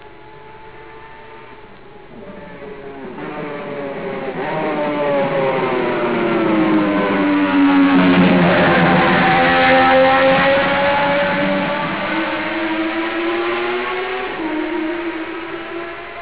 Son bruit inoubliable et cette rangée de six échappements ne peuvent laisser indifférents les amoureux de belles mécaniques.